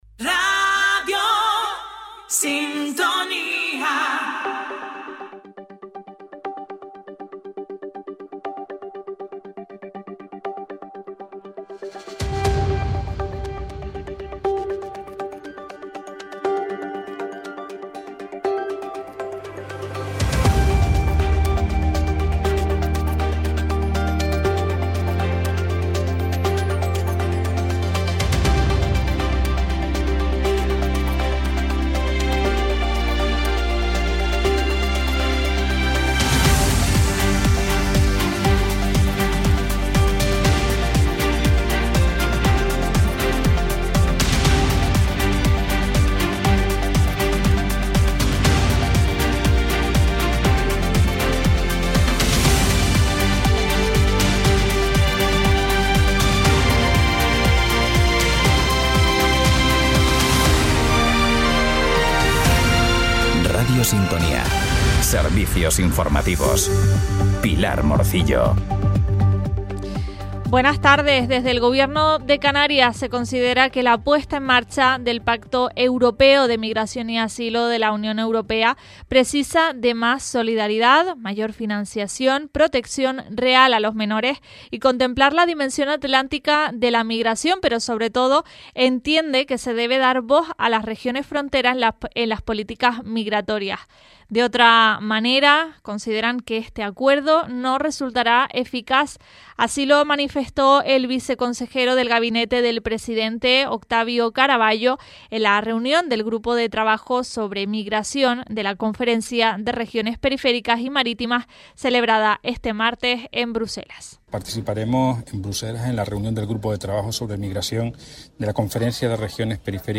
Servicios Informativos